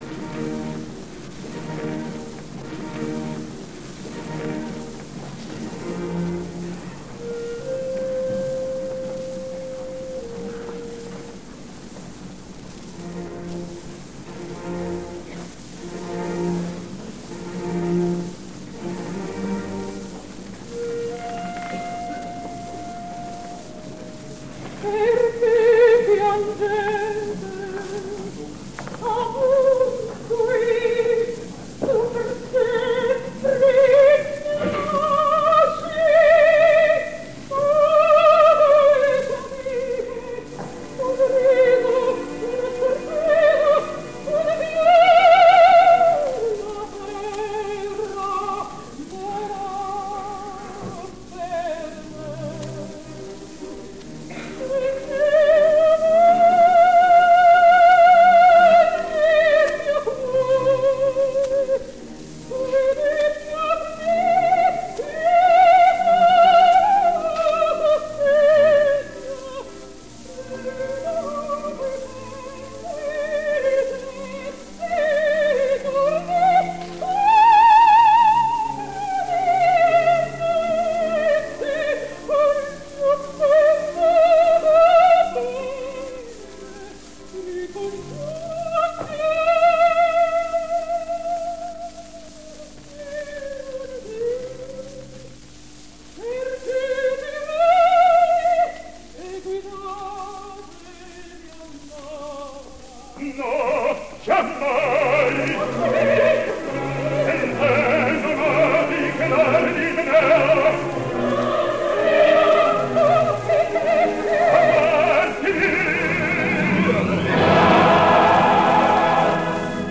Il Trovatore: Perché piangete? – Maria Callas – Mexico City
In questo fortunoso live messicano si fatica a dire in che cosa sia più ammirevole Maria Callas, se nella pregnanza del recitativo, con cui la monacanda Leonora prende congedo dalle amiche e dalla vita secolare, oppure nella facilità con cui regge la tessitura, letale a tante colleghe, di frasi come “Non regge a tanto giubilo rapito il cor” oppure “E questo un sogno, un’estasi”. Un passo in cui la natura, la vocalista e l’interprete trovano un equilibrio e una sintesi che hanno del miracoloso.
Finale-II-Callas-Mexico-City.mp3